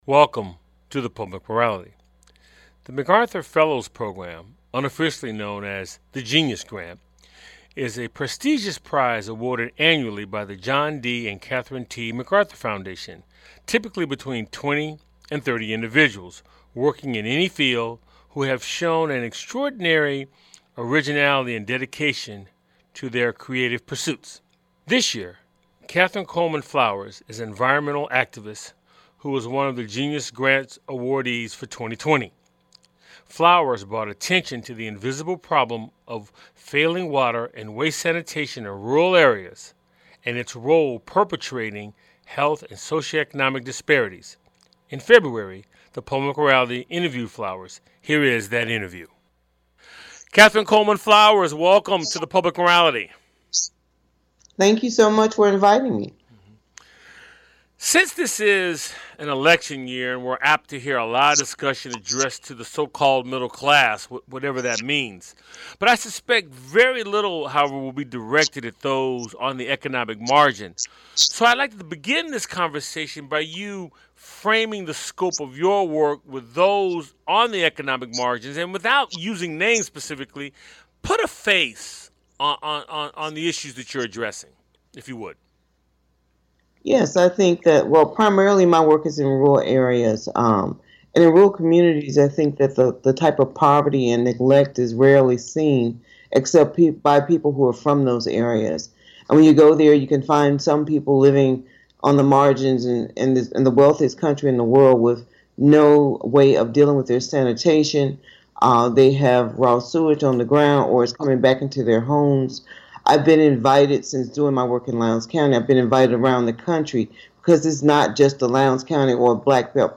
Guest on this show is Catherine Coleman Flowers, Environmental Activist and Recipient of The McAuthur Foundation's "Genius Award".&nbsp
The show airs on 90.5FM WSNC and through our Website streaming Tuesdays at 7:00p.